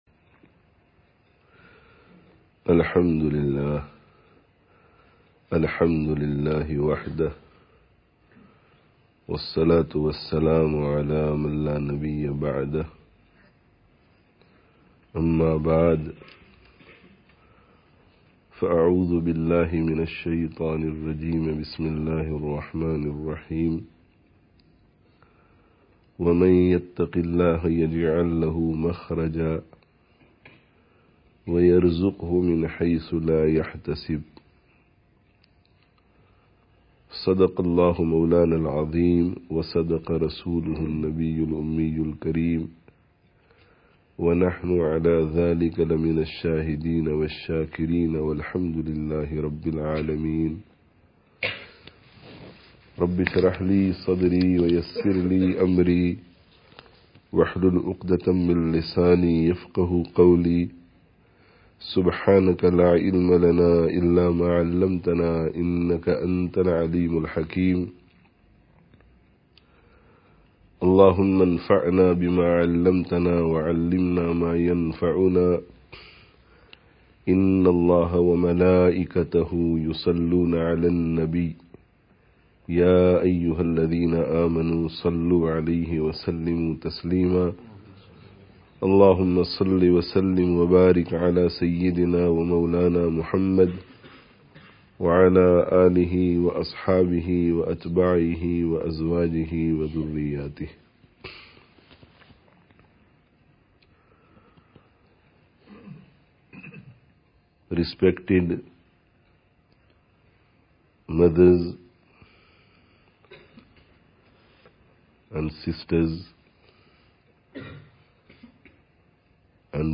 Success Lies in Taqwā [18th Annual Sisters' Gathering] (15/03/15)